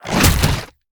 Sfx_creature_rockpuncher_attack_01.ogg